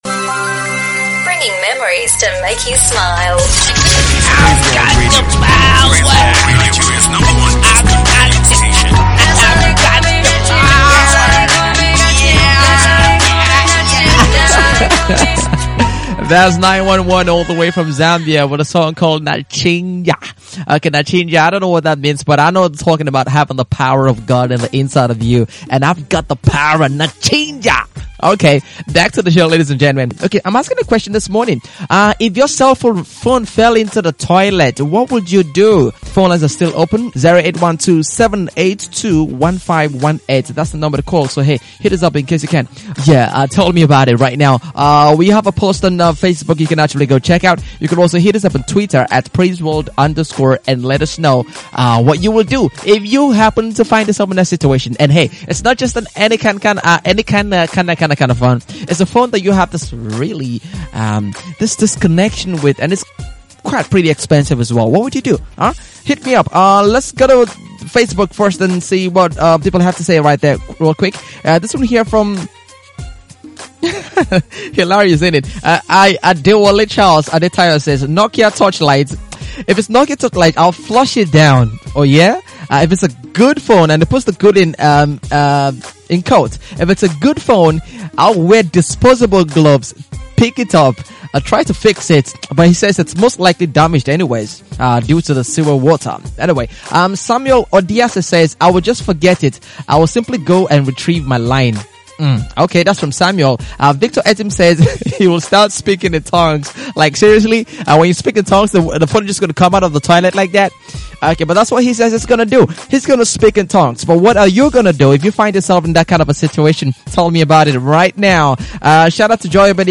Several opinions and experiences were shared by listeners.